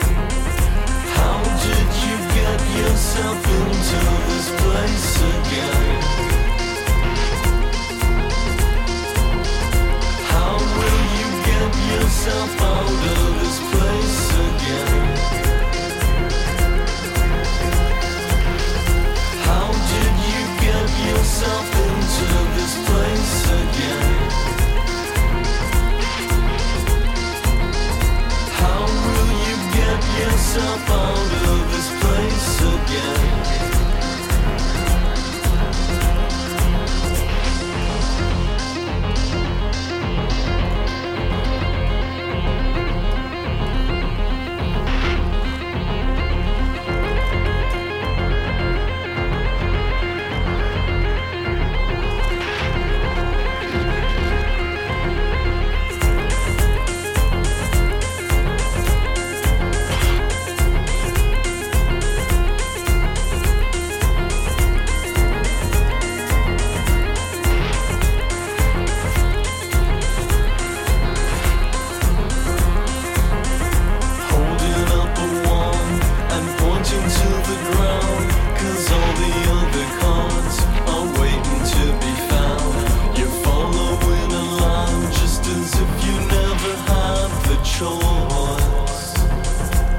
chugging cosmic techno synth pop